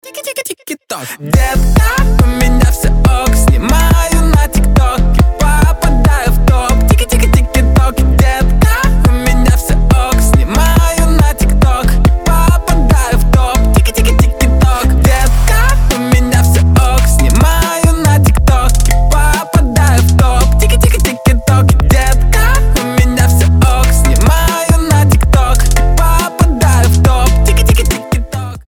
веселые
смешные
Весёлая нарезка для тиктокнутых